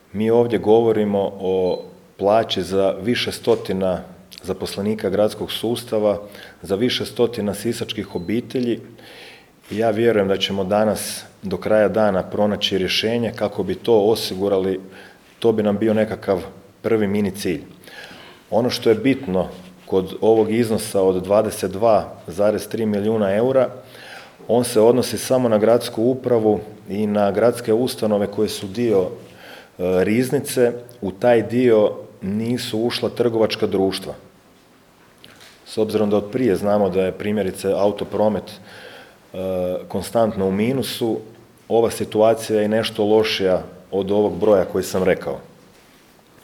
„Više od 22,3 milijuna eura duga, milijunska kreditna zaduženja, nezakonita gradnja, nestali milijuni… Zatečeno stanje u Gradu Sisku nakon odlaska bivše uprave je alarmantno. Nije riječ samo o financijama, riječ je o duboko narušenom sustavu u kojem su ugrožene osnovne funkcije grada”, istaknuo je danas na tiskovnoj konferenciji novi gradonačelnik Siska Domagoj Orlić